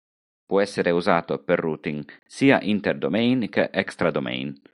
Hyphenated as èx‧tra Pronounced as (IPA) /ˈɛks.tra/